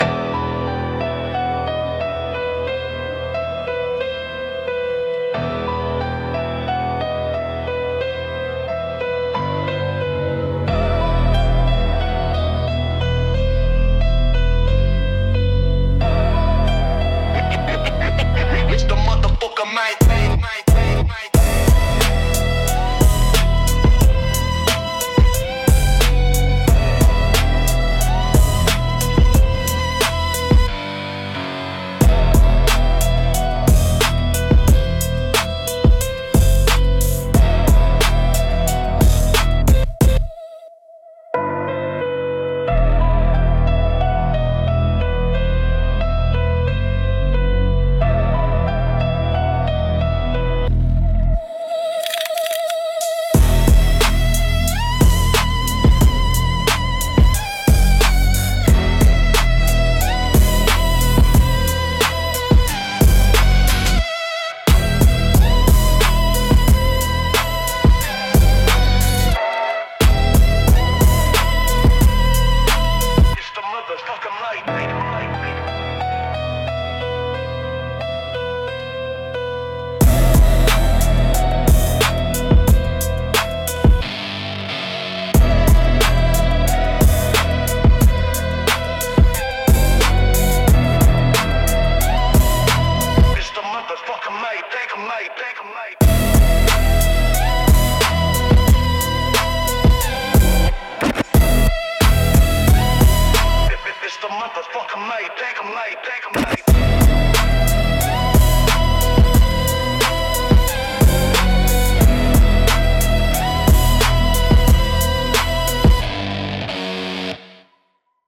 Instrumentals - Trap Noir